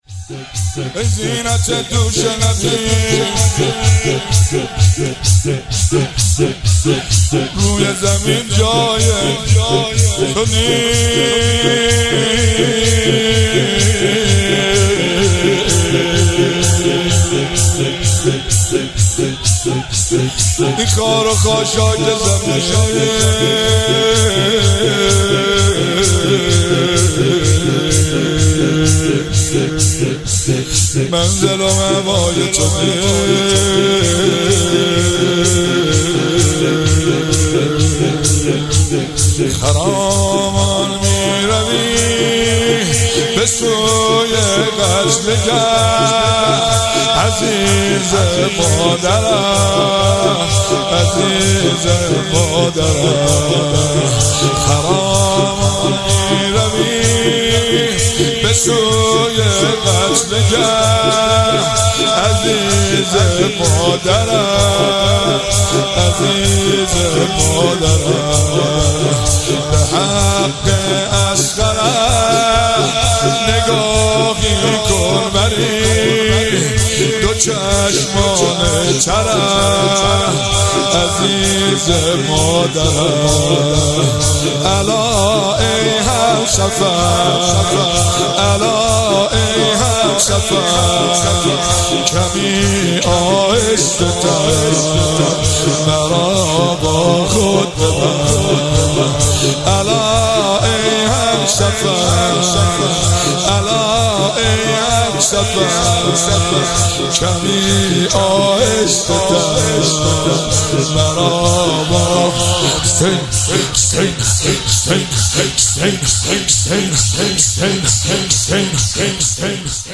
شور
ایام فاطمیه۹۷ هیئت فاطمیون قم